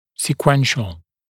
[sɪ’kwenʃl][си’куэншл]последовательный, последующий